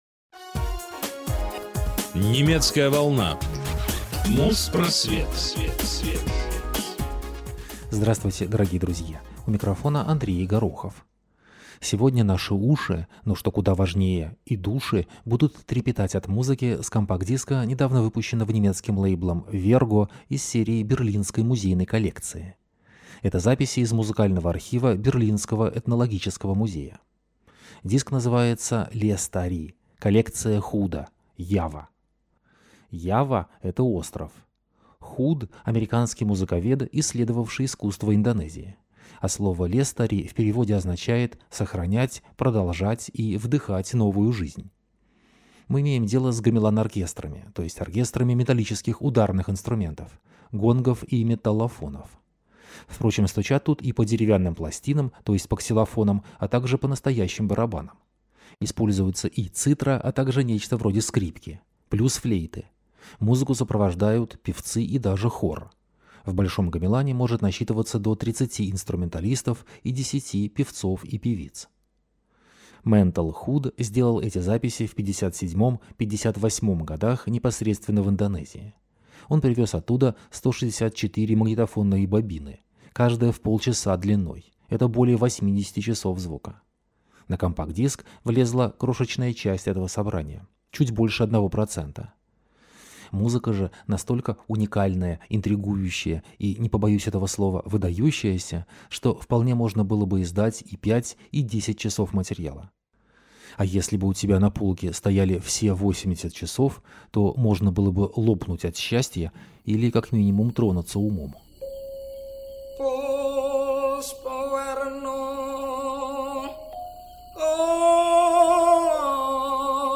Музпросвет 290 от 1 марта 2008 года - Архивные записи индонезийских гамелан-оркестров | Радиоархив